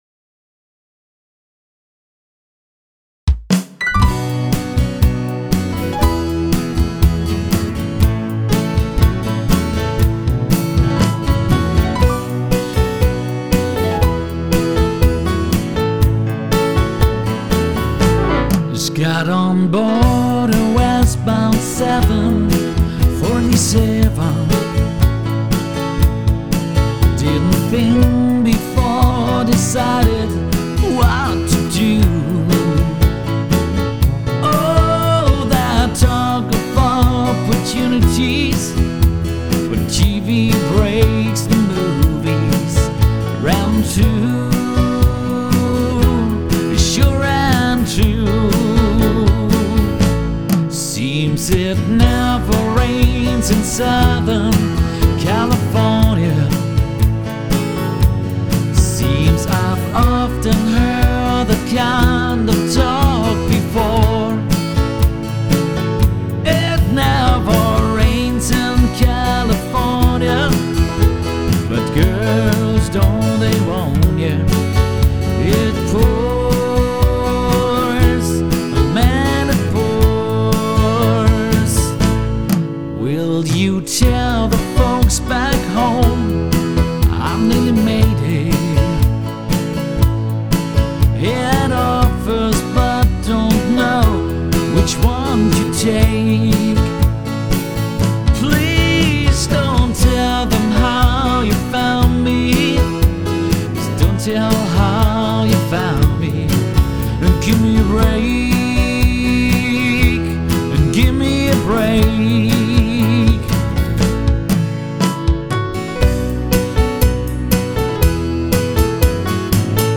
• Coverband
• Sänger/in